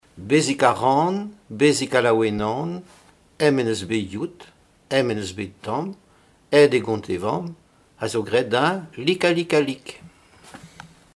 formulette enfantine : jeu des doigts
Pièce musicale inédite